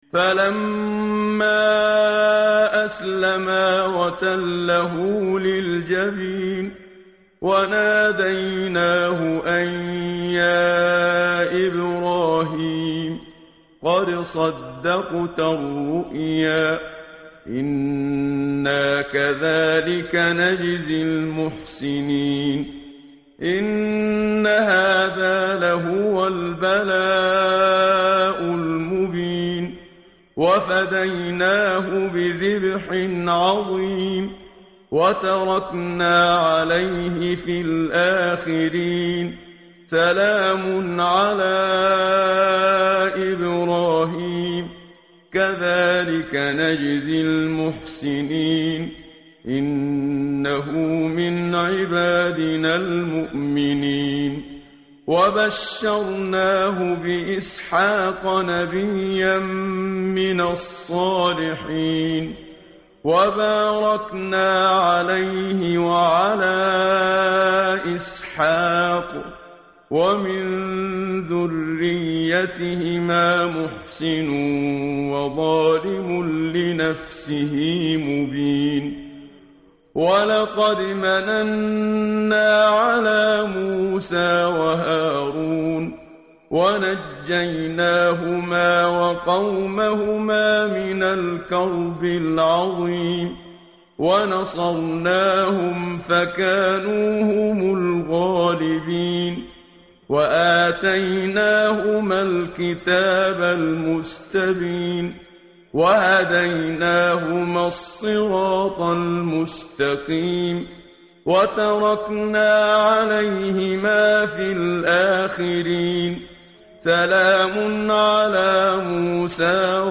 متن قرآن همراه باتلاوت قرآن و ترجمه
tartil_menshavi_page_450.mp3